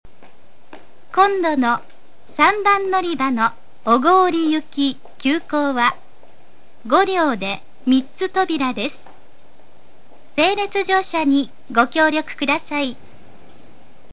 ＜スピーカー＞　天井埋込型
＜曲名（本サイト概要）＞　西鉄主要　／　全線-女性放送
○案内放送（急行・小郡）